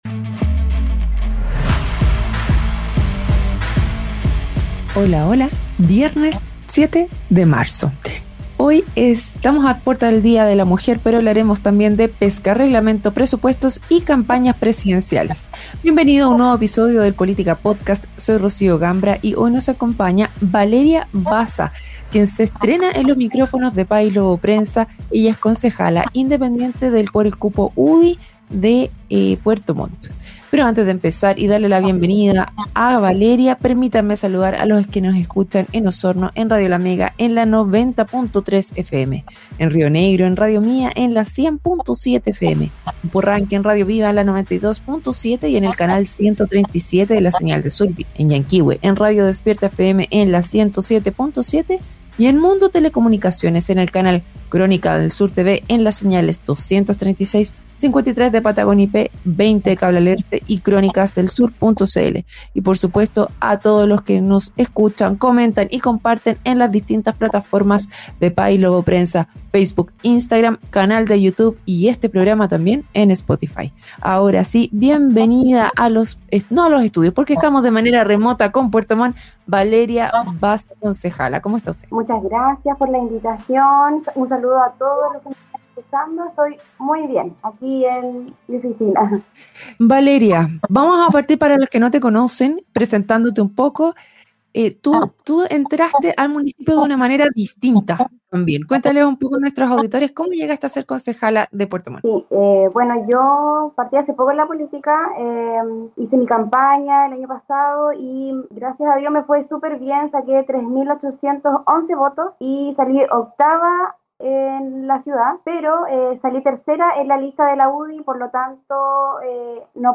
🎙💬 Concejala Valeria Baza aborda desafíos de Puerto Montt en Política Podcast. Educación, pesca artesanal y el rol de las mujeres en la política fueron temas clave en la conversación.